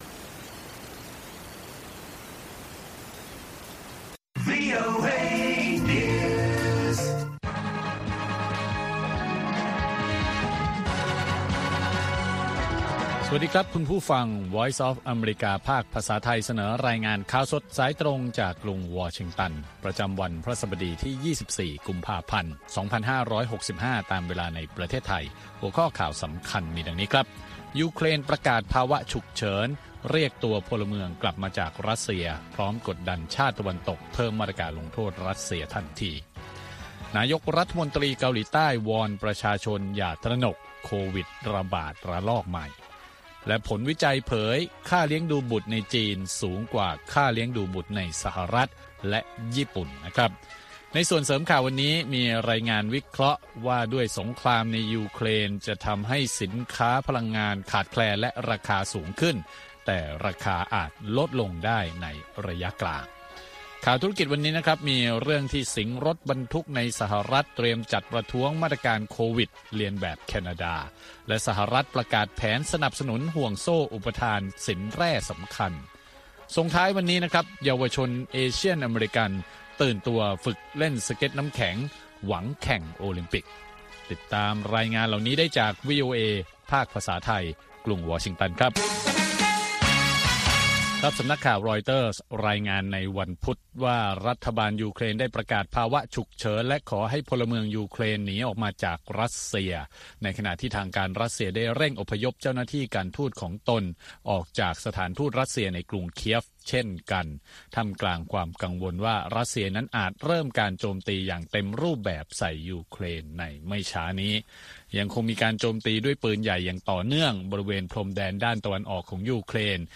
ข่าวสดสายตรงจากวีโอเอ ภาคภาษาไทย 8:30–9:00 น. ประจำวันพฤหัสบดีที่ 24 กุมภาพันธ์ 2565 ตามเวลาในประเทศไทย